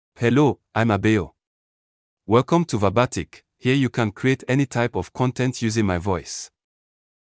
MaleEnglish (Nigeria)
Abeo is a male AI voice for English (Nigeria).
Voice sample
Listen to Abeo's male English voice.
Abeo delivers clear pronunciation with authentic Nigeria English intonation, making your content sound professionally produced.